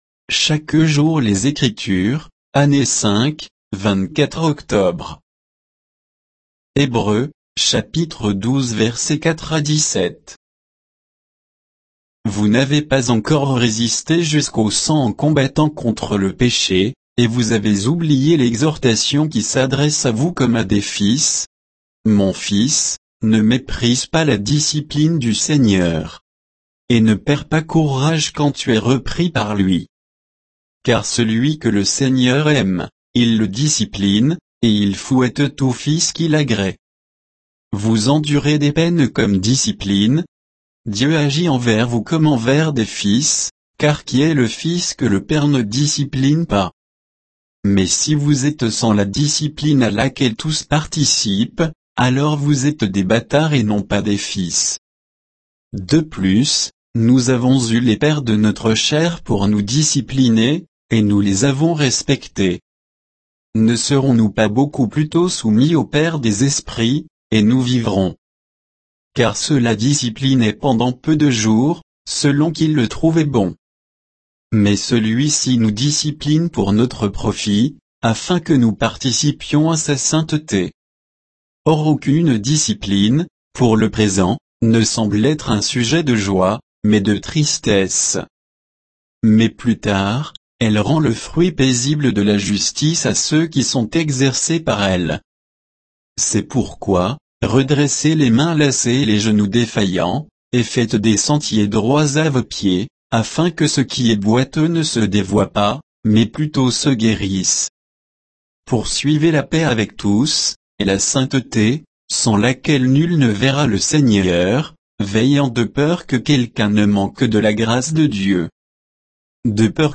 Méditation quoditienne de Chaque jour les Écritures sur Hébreux 12